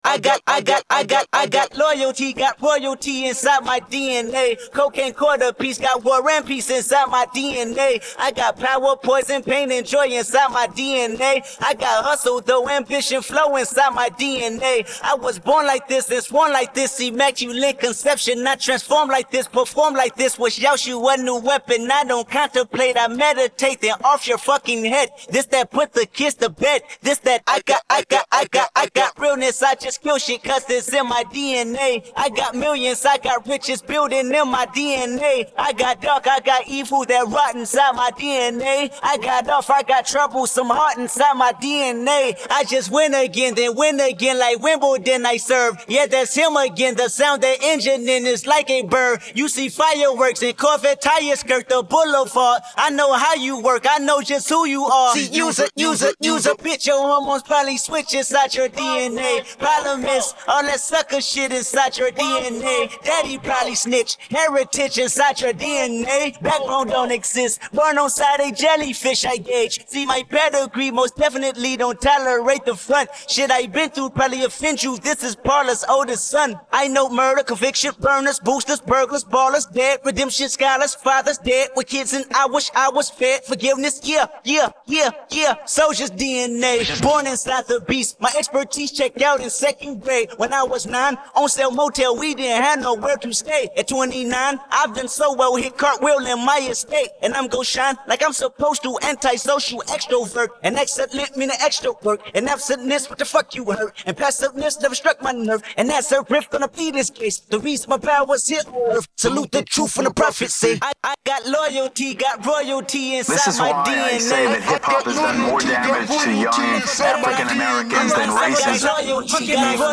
Stimme